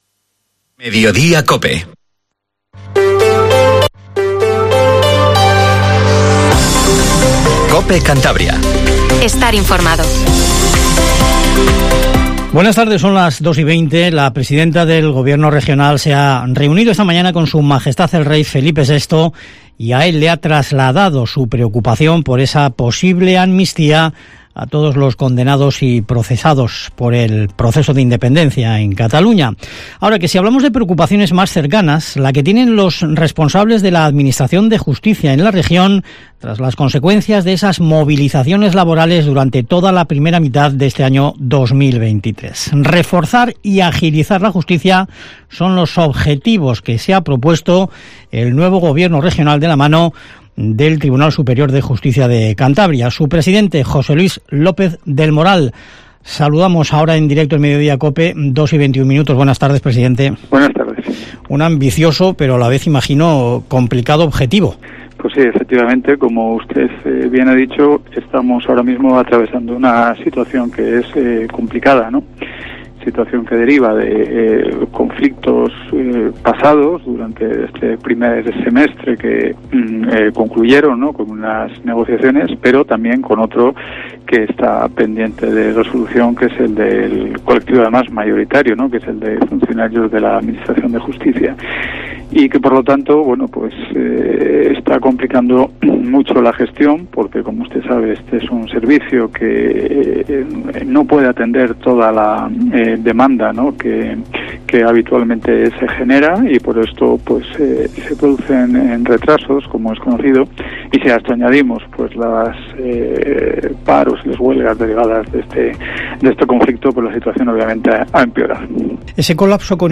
Informativo MEDIODIA en COPE CANTABRIA 14:20